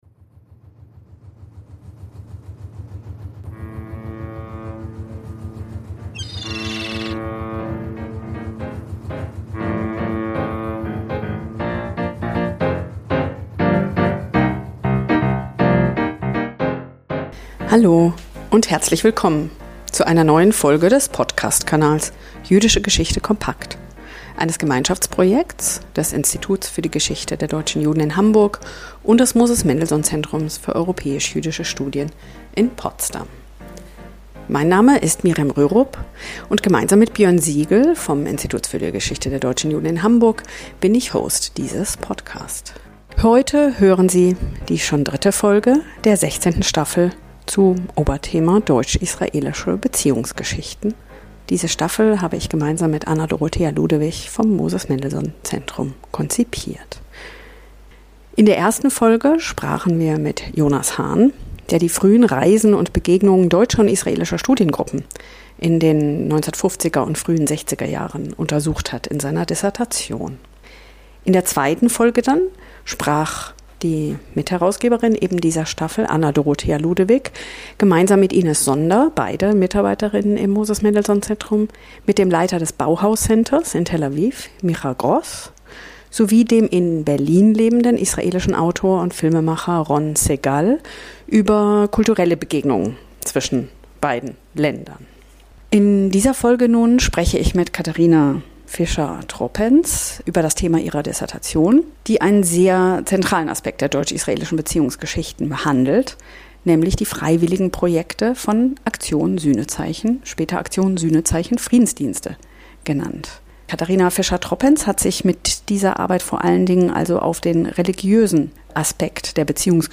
Die ersten Gespräche sind weit vor den aktuellen Entwicklungen aufgenommen worden.